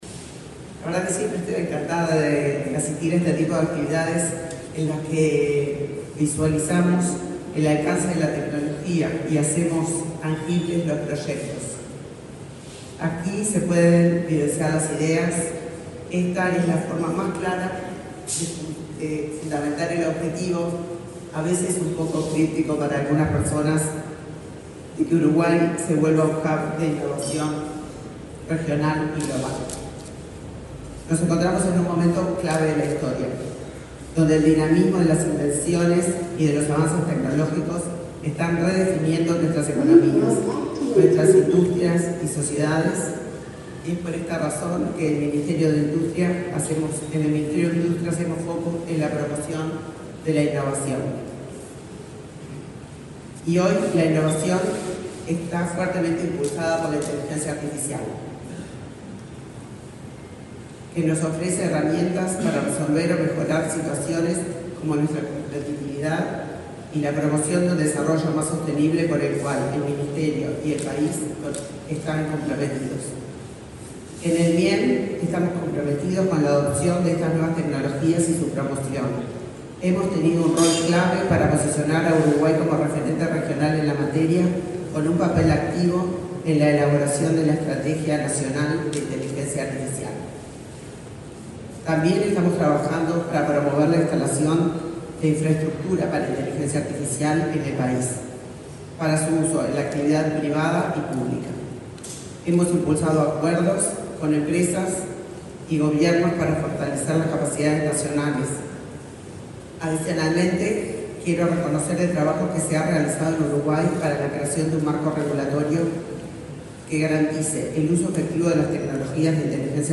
Palabras de la ministra de Industria, Elisa Facio
Este miércoles 28 en el Laboratorio Tecnológico del Uruguay, la ministra de Industria, Elisa Facio, participó en la muestra Newlab Uruguay, la